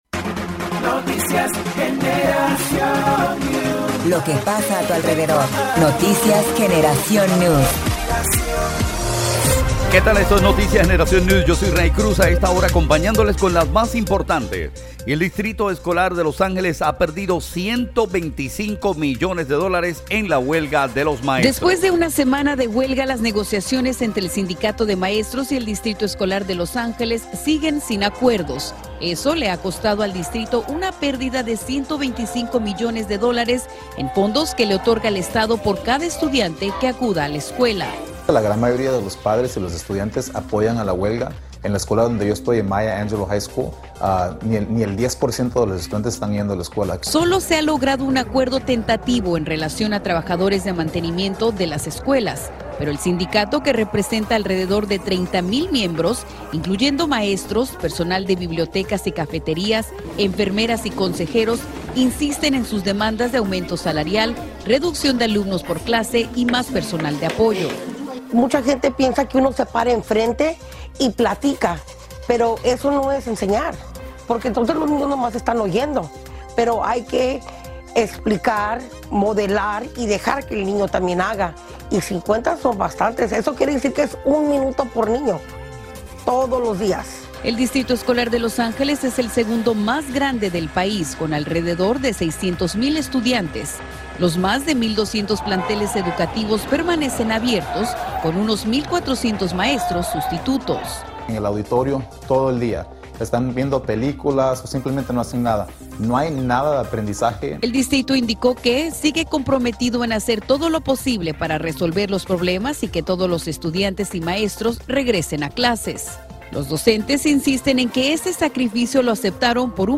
Segmento de Noticias 2 - 22 de enero